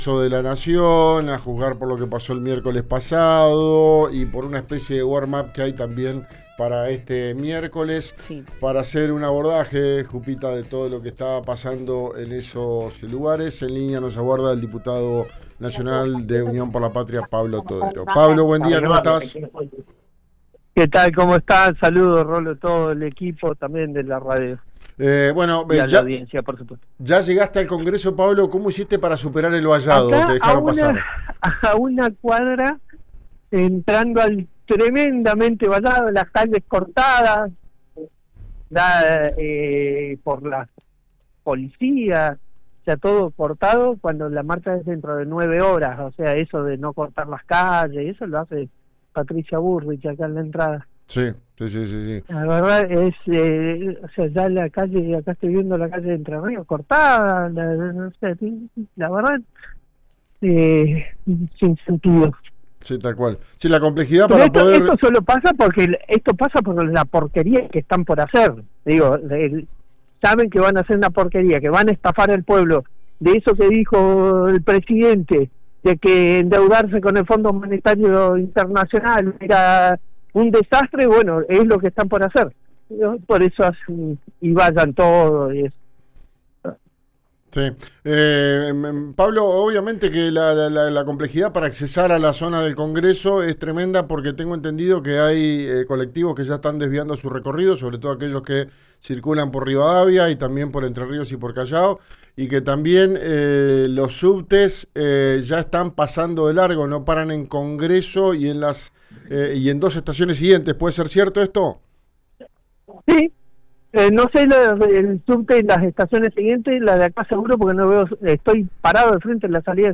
Escuchá a Pablo Todero, diputado nacional de Neuquén por Unión por la Patria, en RÍO NEGRO RADIO:
El diputado nacional Todero dialogó con RÍO NEGRO RADIO en su ingreso al recinto del Congreso.